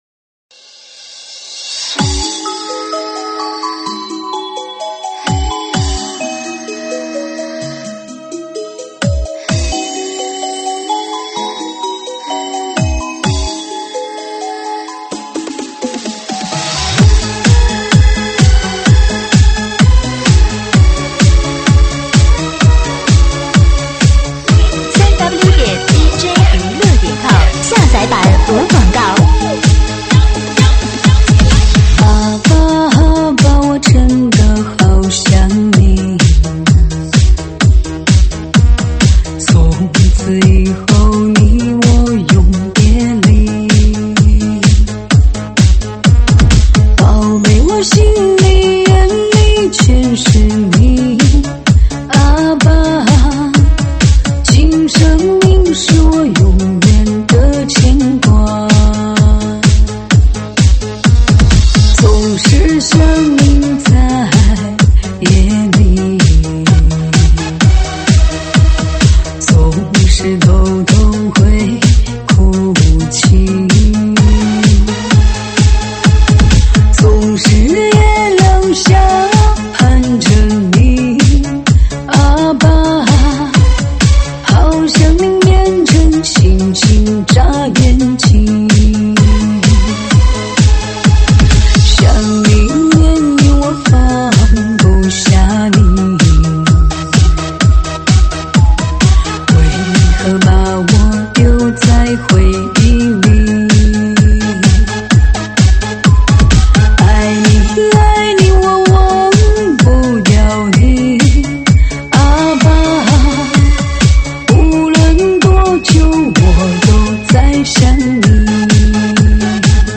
舞曲类别：快四